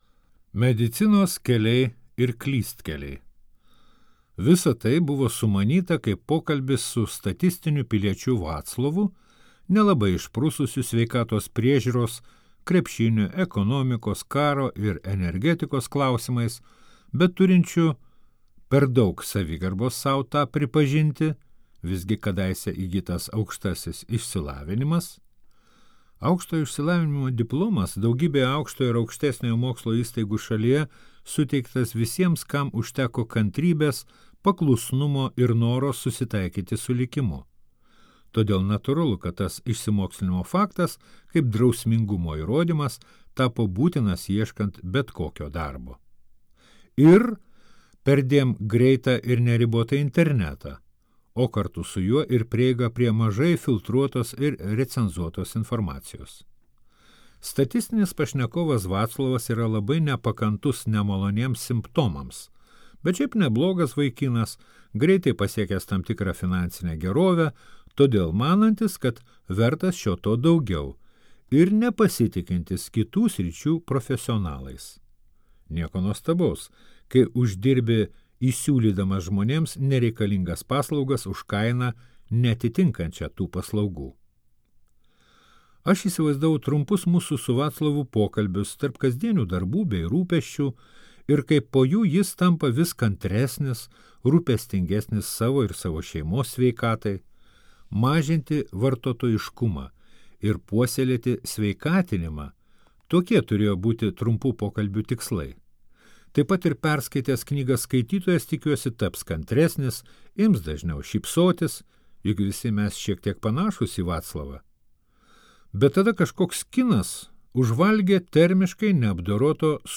Sveikatos mitai ir paklydimai | Audioknygos | baltos lankos